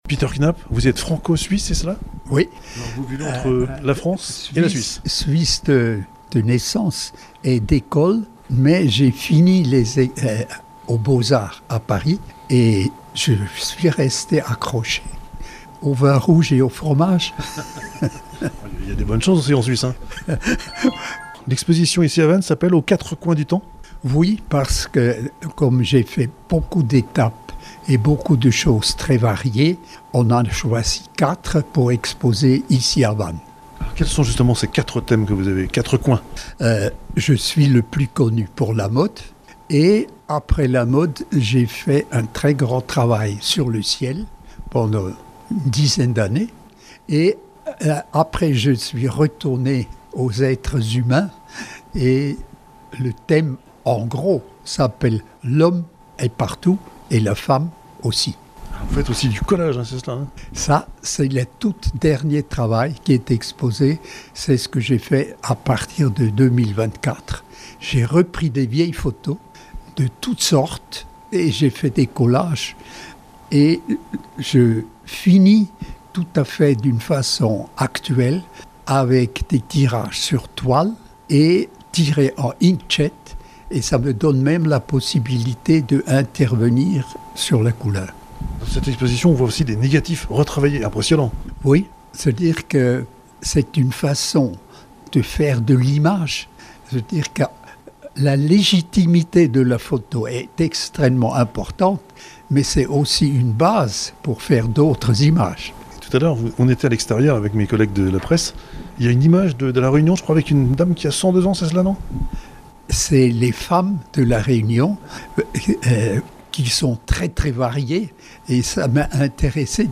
Interview de Peter Knapp à Vannes